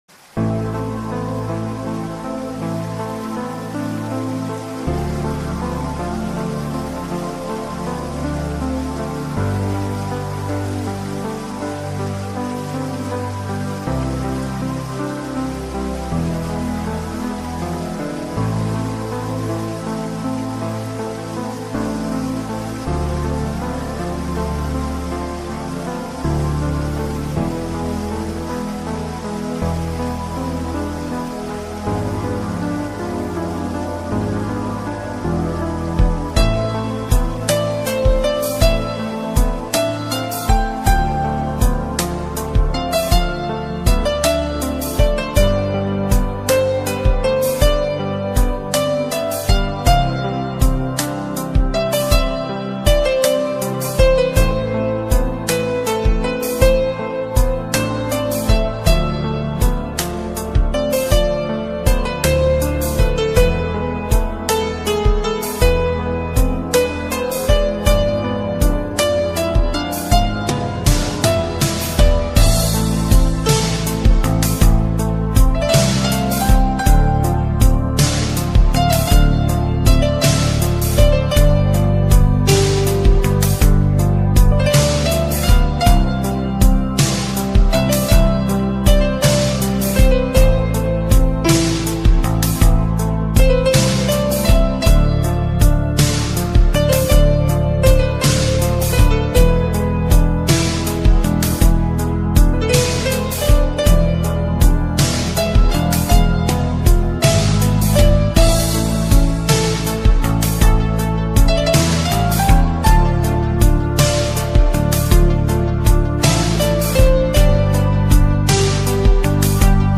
Semi-Classic